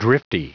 Prononciation du mot drifty en anglais (fichier audio)
Prononciation du mot : drifty